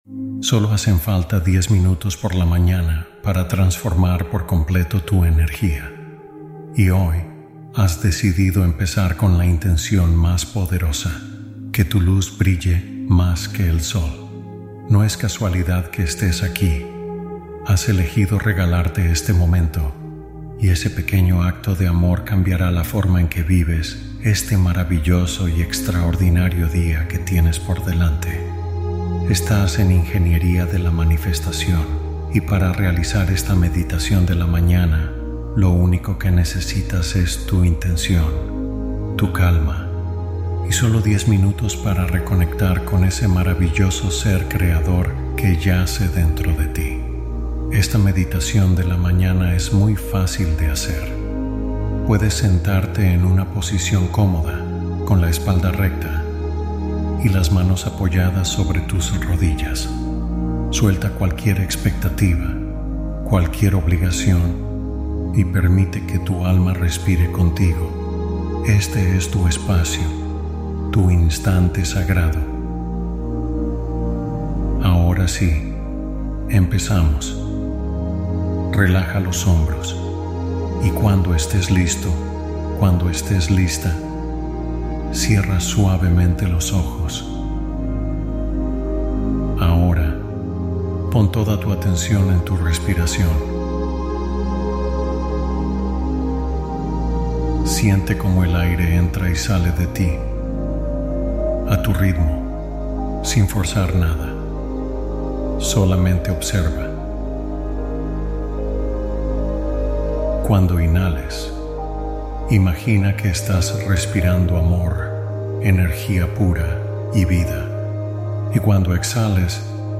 Nueva Meditación Matutina de 10 Minutos Para Atraer Milagros